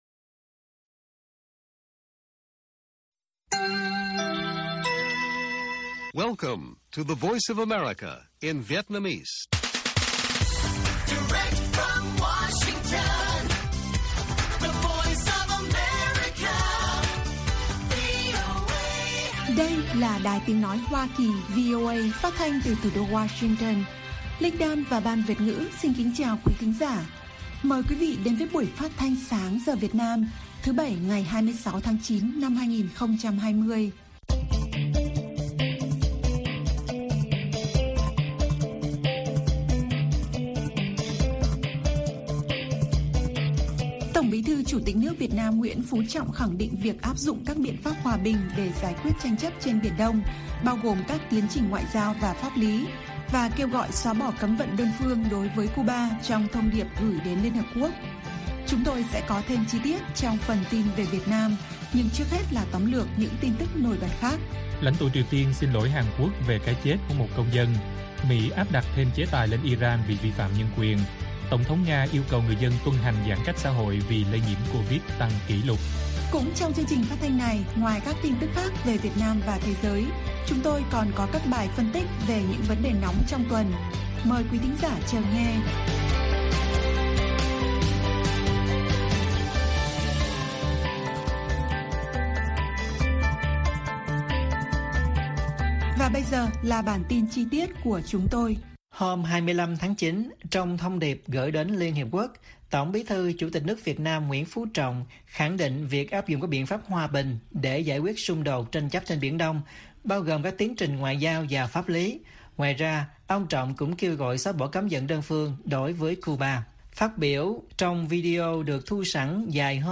Bản tin VOA ngày 26/9/2020